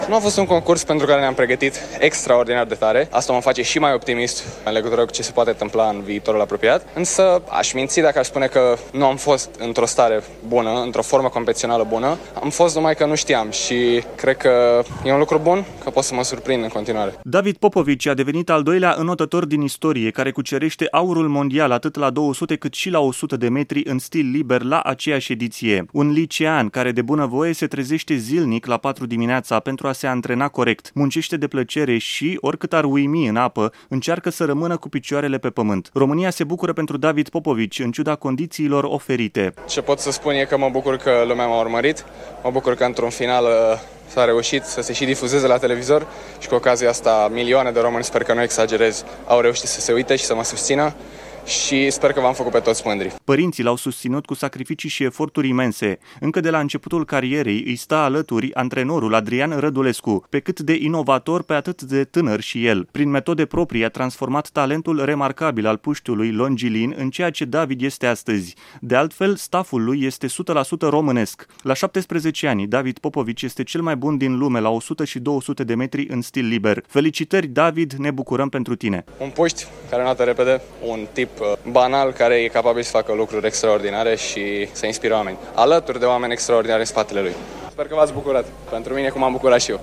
într-un reportaj